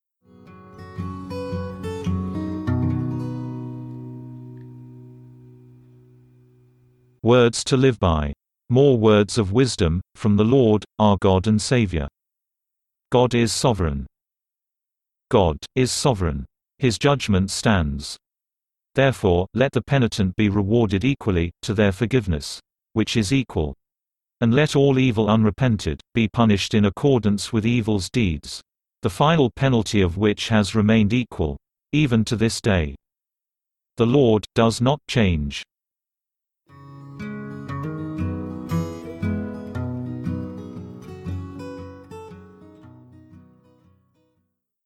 File:WTLB 132 God Is Sovereign (read by text-to-speech).mp3 - The Volumes of Truth
WTLB_132_God_Is_Sovereign_(read_by_text-to-speech).mp3